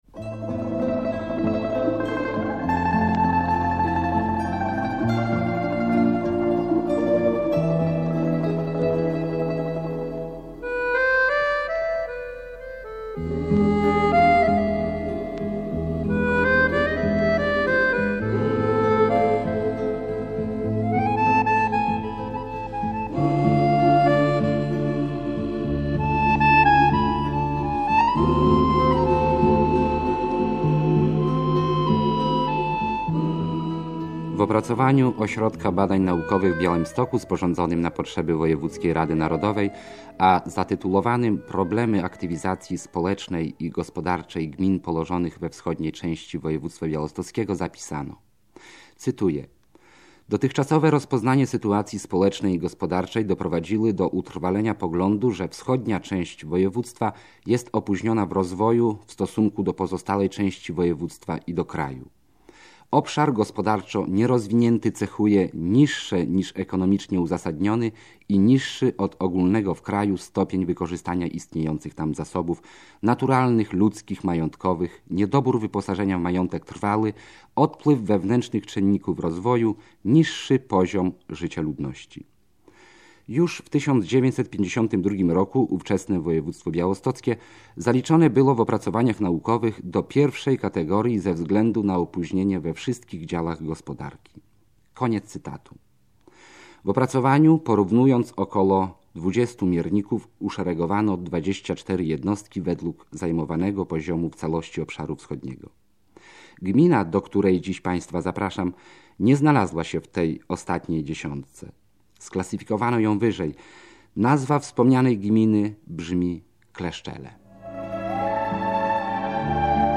Miejsce nagrania: Kleszczele i okolice